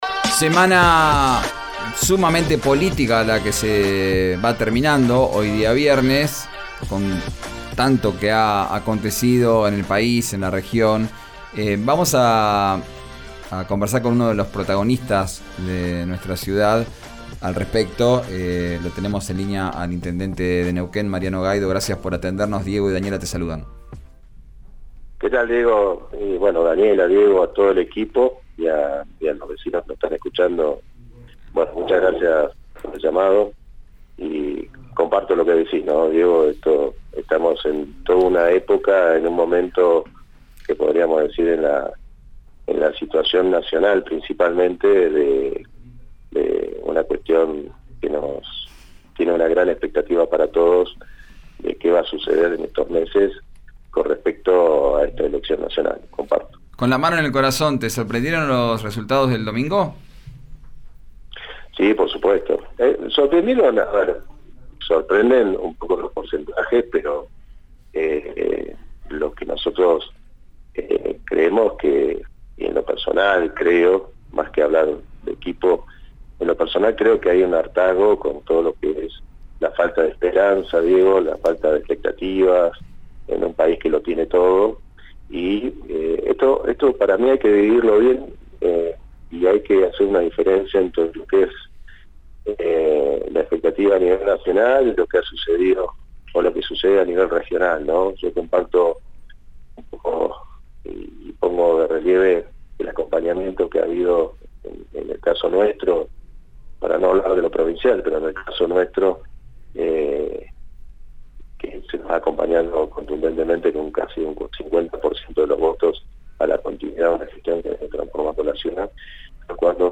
El intendente de Neuquén opinó sobre el triunfo del candidato de La Libertad Avanza, en diálogo con RÍO NEGRO RADIO.
El intendente de Neuquén, Mariano Gaido, opinó sobre el resultado de las PASO 2023, tras el triunfo de Javier Milei. En comunicación con RÍO NEGRO RADIO, vio con preocupación el posible ascenso del candidato a presidente por La Libertad Avanza y coincidió con el gobernador electo de Río Negro, Alberto Weretilneck, respecto a que – de ganar – se le será complicado gobernar sin el acompañamiento de las instituciones.